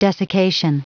Prononciation du mot desiccation en anglais (fichier audio)
Prononciation du mot : desiccation